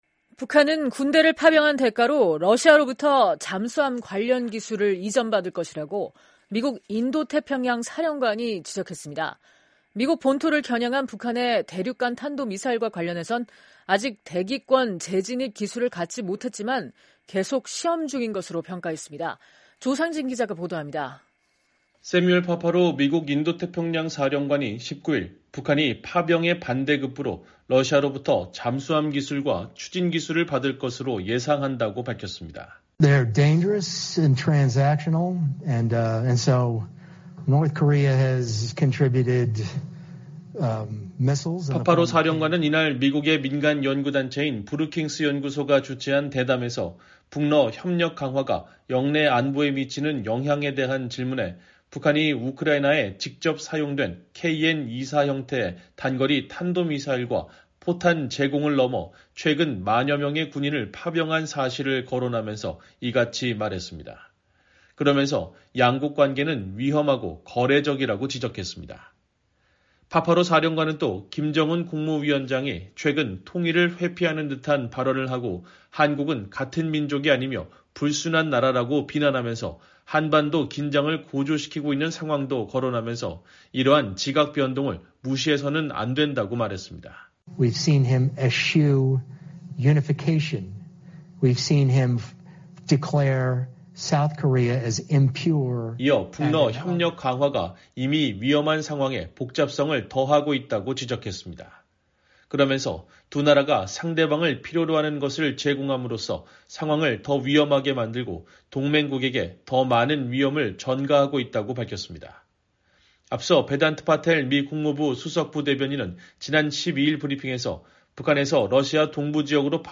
새뮤얼 파파로 미국 인도태평양사령관이 19일 민간연구단체인 ‘브루킹스연구소’가 주최한 대담에서 발언하고 있다.